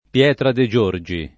pL$tra de J1rJi] (Lomb.), Pietra Marazzi [